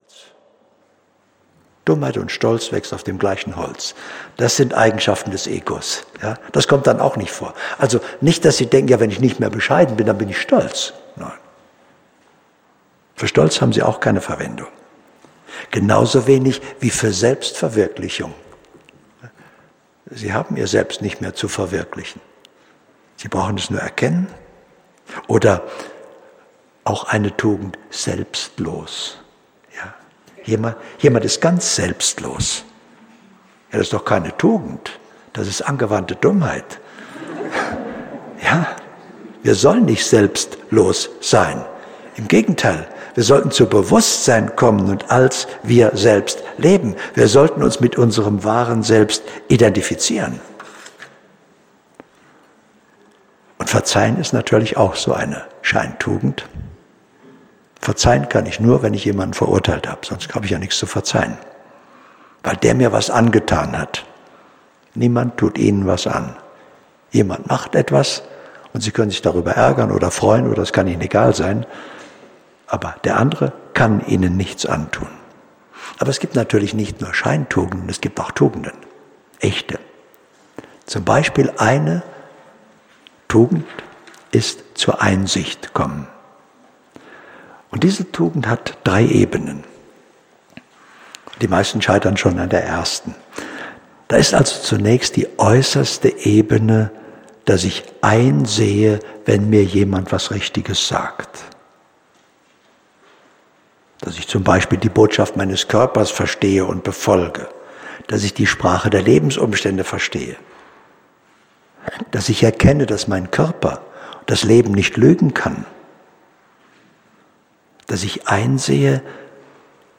Hörbuch Selbstbewusstsein und Lebensvision - Golden Classics.